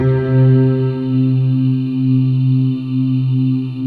Index of /90_sSampleCDs/USB Soundscan vol.28 - Choir Acoustic & Synth [AKAI] 1CD/Partition C/13-MARJOLIE